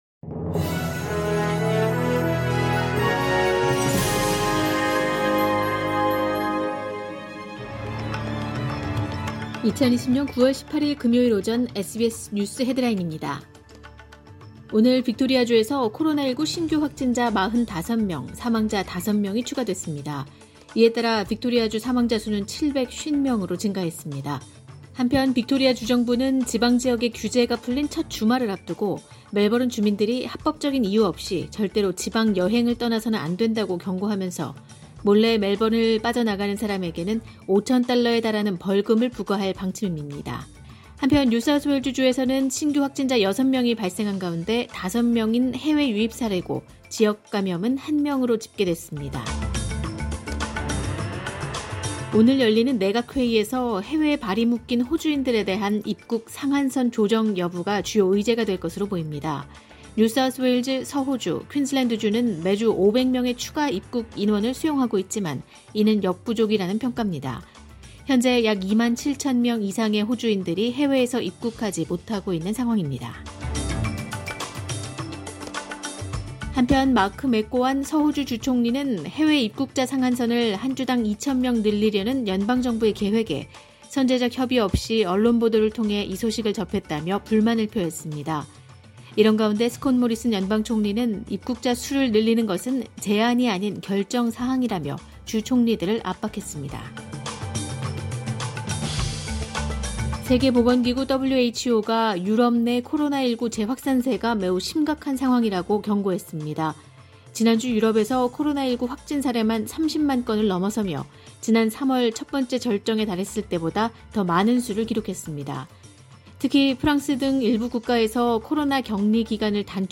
2020년 9월 18일 금요일 오전의 SBS 뉴스 헤드라인입니다.